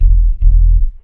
BASS 1 115-R.wav